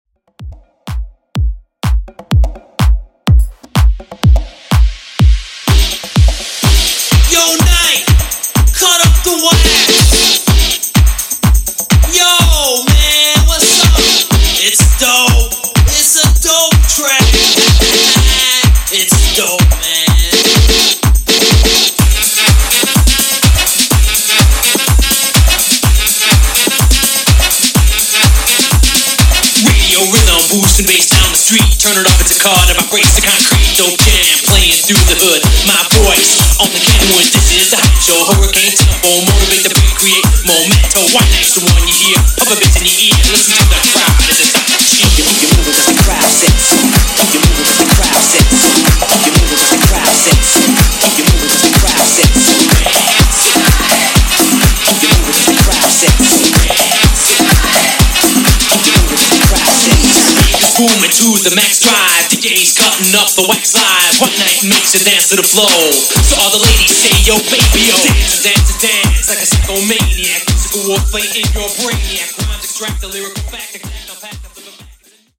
90's , OLD SCHOOL HIPHOP , RE-DRUM 100 Clean